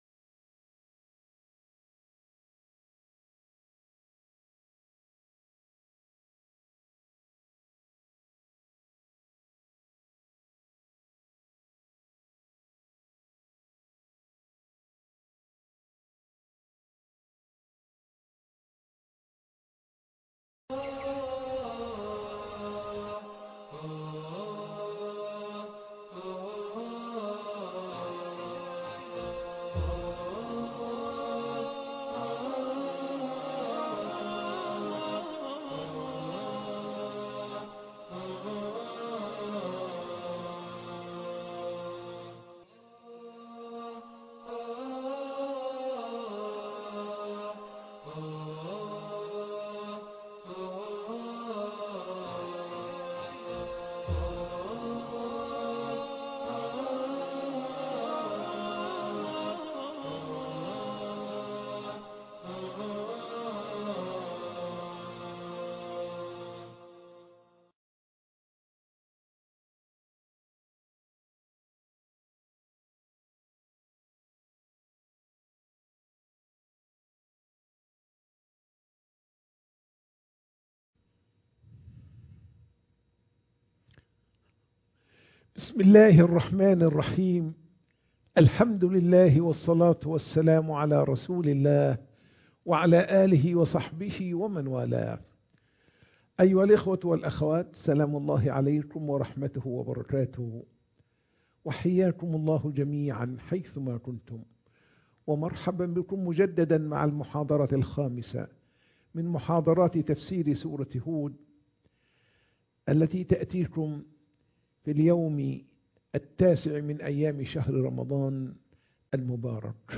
محاضرة التفسير - سورة يوسف 35